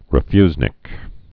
(rĭ-fyznĭk)